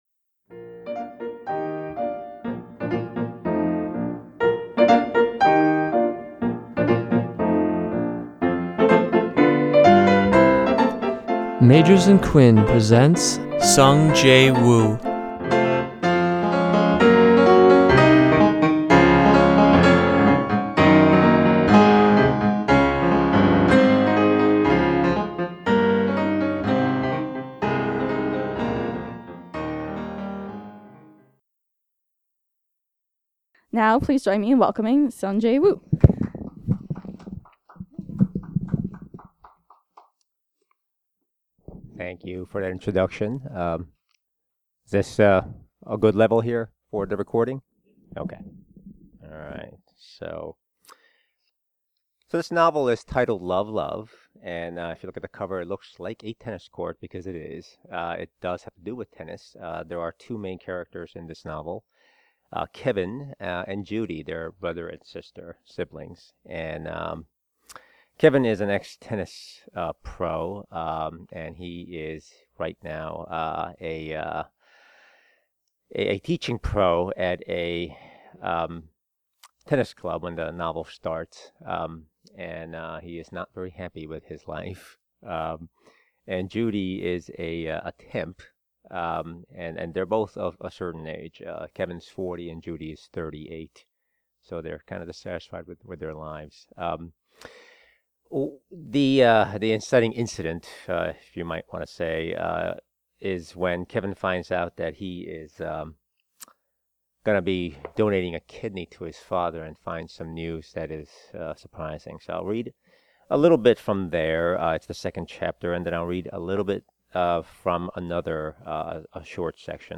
Enjoy this reading and Q&A from this talented novelist! https
Recorded at Magers and Quinn Booksellers on September 21, 2015.